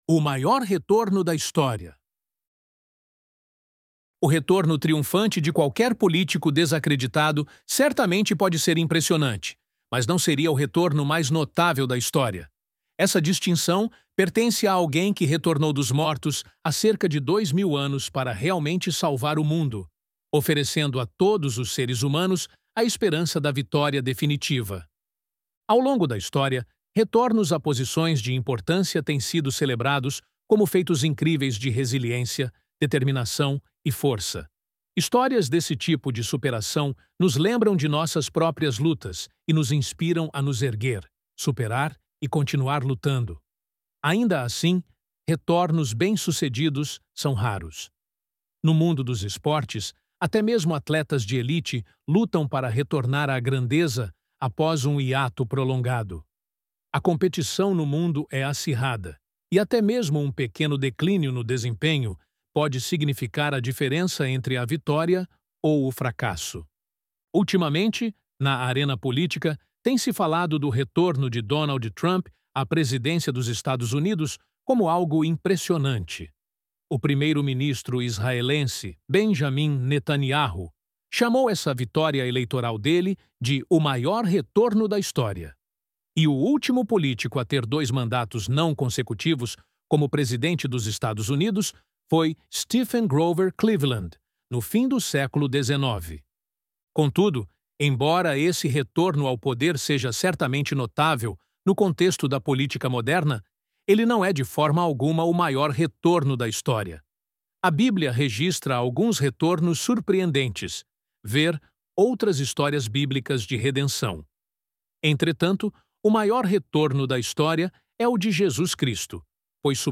ElevenLabs_O_Maior_Retorno_da_História!.mp3